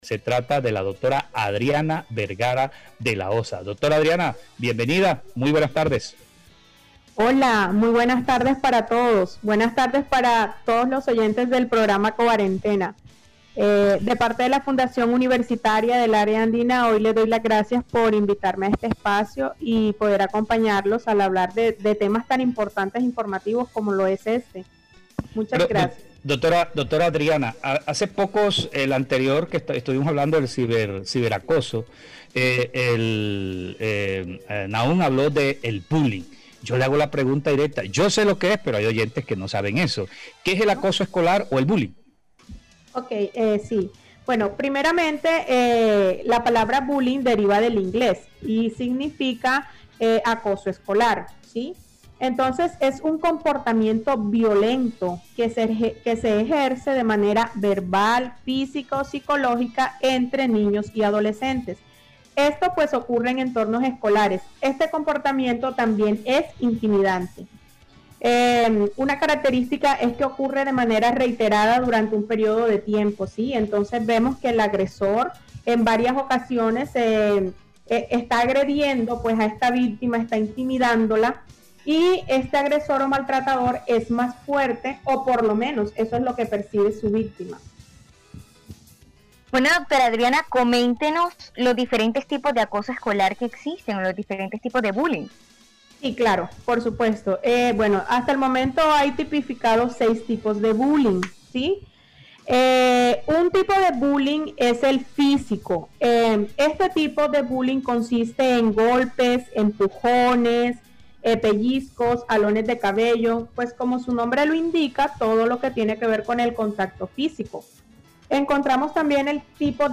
(Entrevista) Psicóloga clínica despeja dudas acerca del bullying o acoso escolar